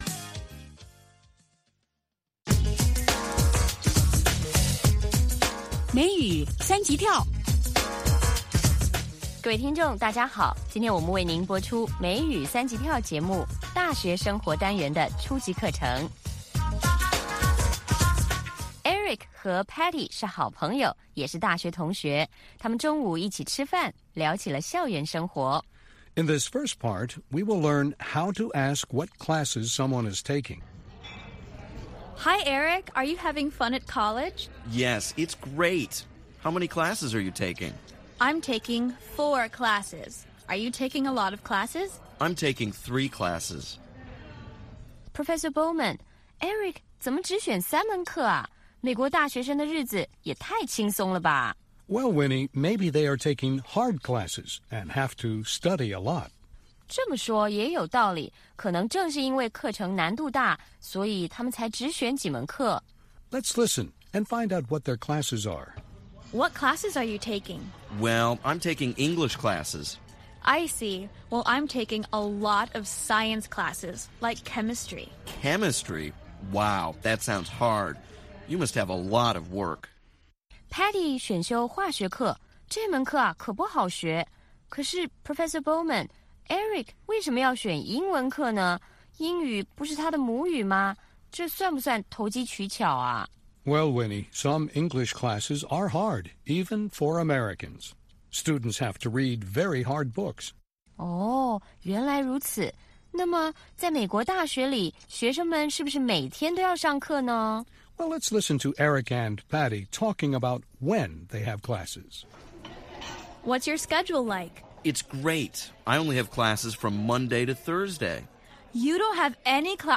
北京时间下午5-6点广播节目。广播内容包括收听英语以及《时事大家谈》(重播)